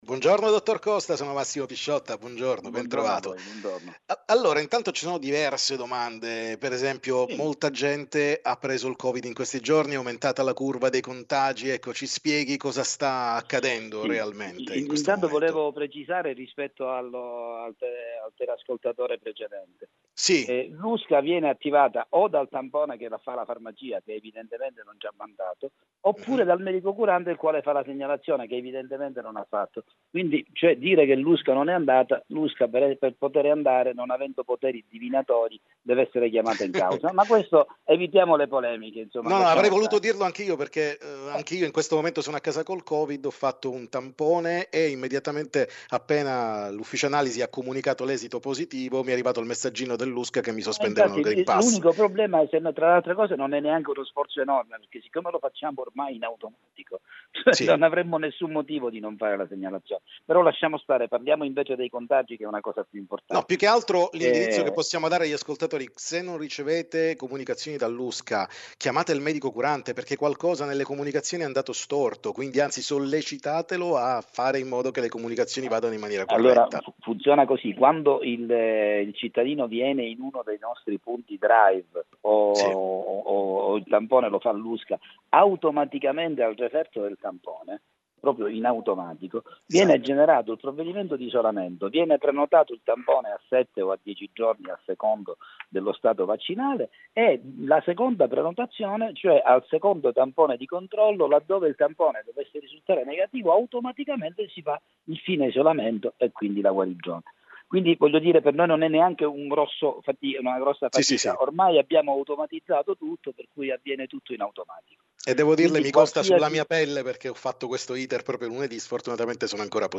TM Intervista